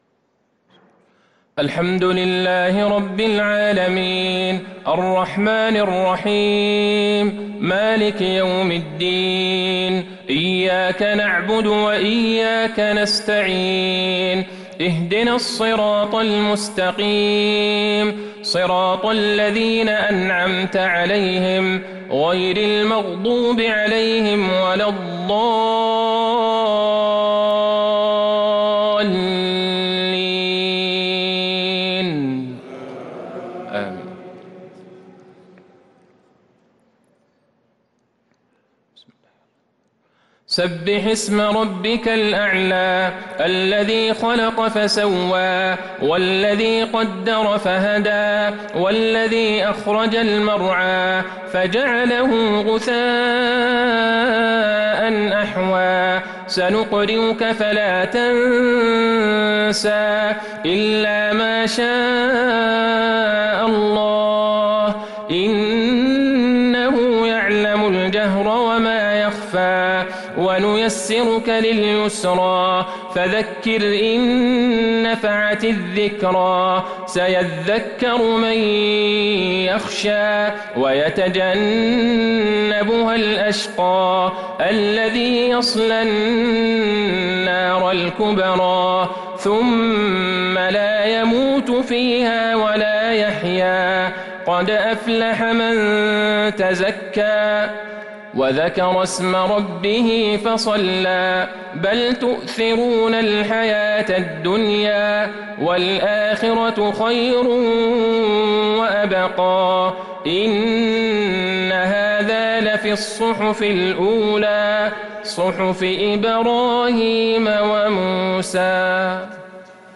صلاة الجمعة ٢٦ محرم ١٤٤٣هـ | سورتي الأعلى والغاشية | salah jumua prayer from Surah Al-Aala & Al-Ghashyiah 3-9-2021 > 1443 🕌 > الفروض - تلاوات الحرمين